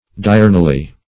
diurnally - definition of diurnally - synonyms, pronunciation, spelling from Free Dictionary Search Result for " diurnally" : The Collaborative International Dictionary of English v.0.48: Diurnally \Di*ur"nal*ly\, adv.